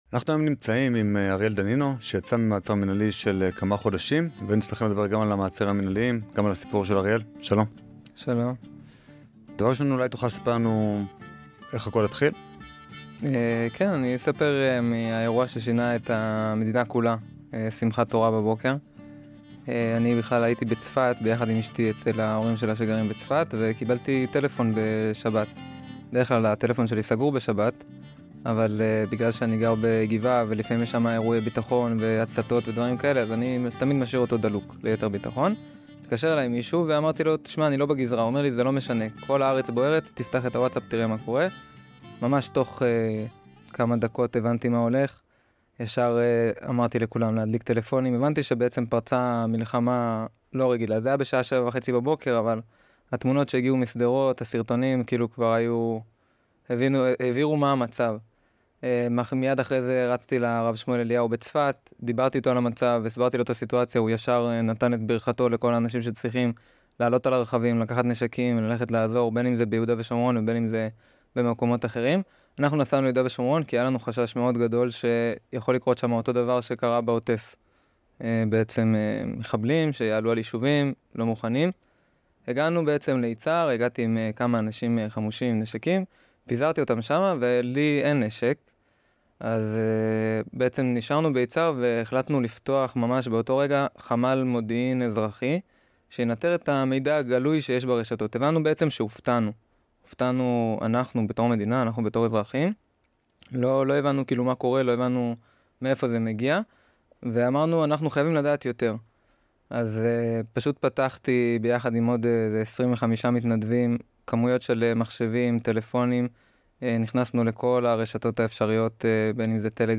ראיון בלעדי